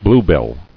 [blue·bill]